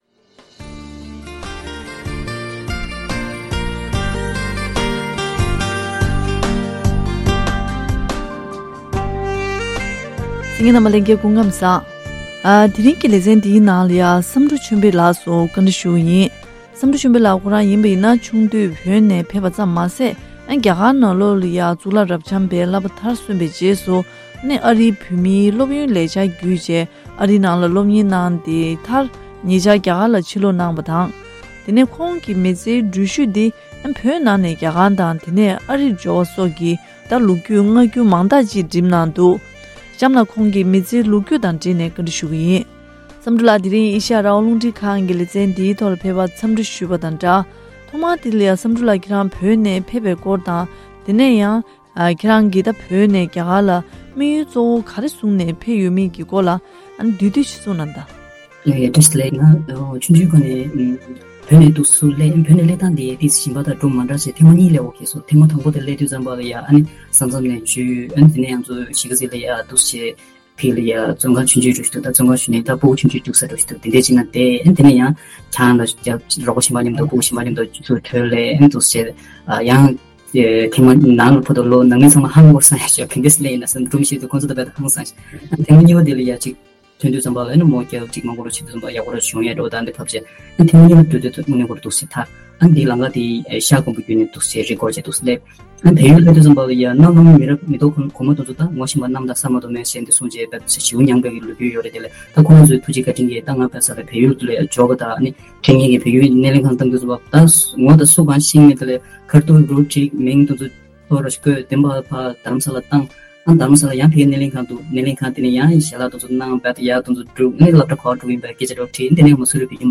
གཤམ་ལ་ཨེ་ཤེ་ཡ་རང་དབང་རླུང་འཕྲིན་ཁང་གི་གསར་འགོད་པ་